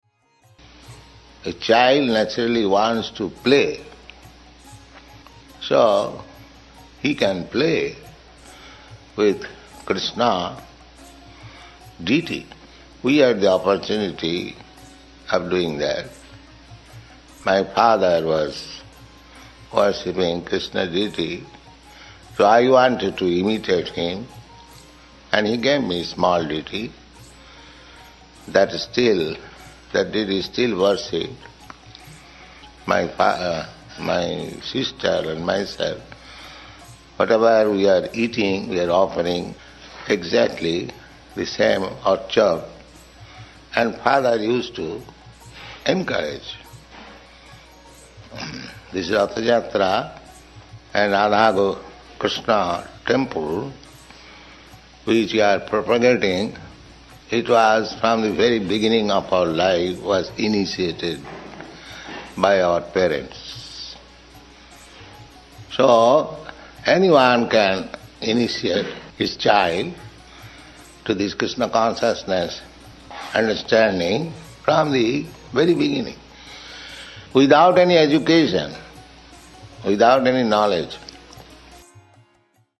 (720601 – Lecture SB 02.03.15 – Los Angeles)